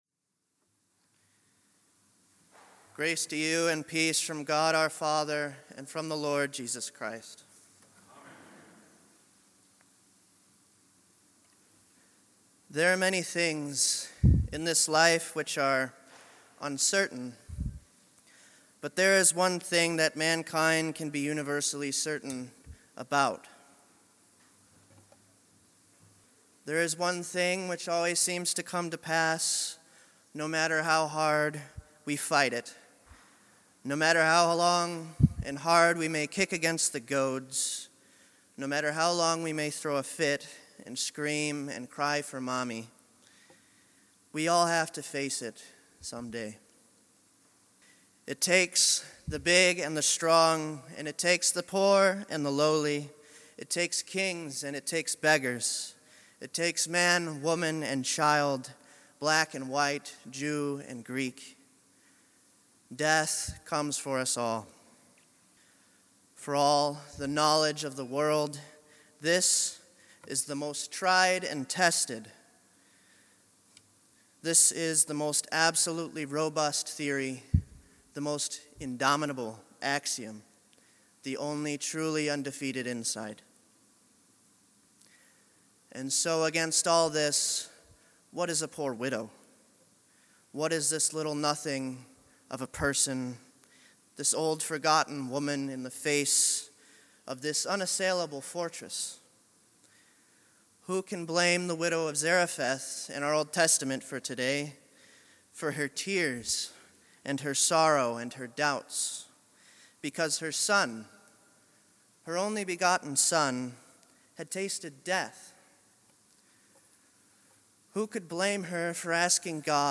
Sixteenth Sunday after Trinity